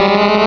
Cri de Queulorior dans Pokémon Rubis et Saphir.